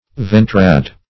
Search Result for " ventrad" : The Collaborative International Dictionary of English v.0.48: Ventrad \Ven"trad\, adv.